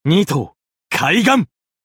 刀剑乱舞_Nikkari-doubleattackcallout.mp3